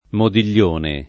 modiglione [ modil’l’ 1 ne ]